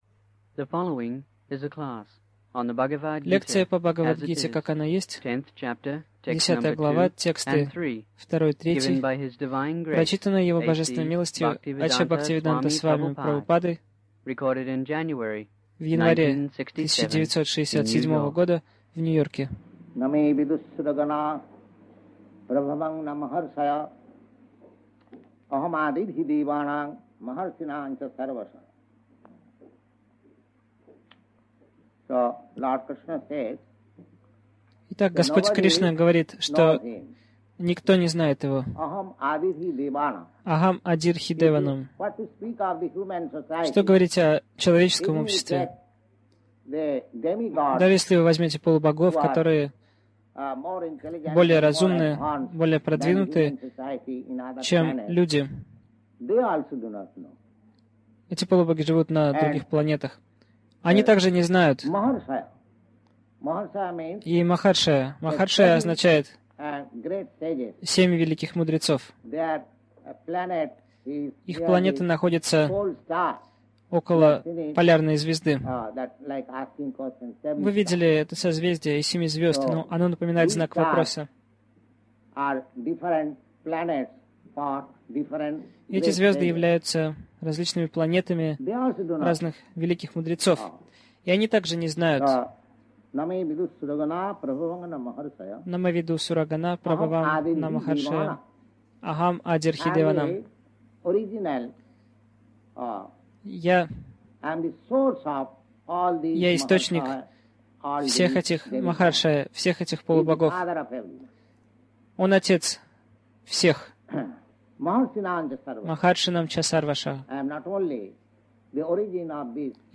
Милость Прабхупады Аудиолекции и книги 01.01.1967 Бхагавад Гита | Нью-Йорк БГ 10.02-03 — Процесс постижения Бога Загрузка...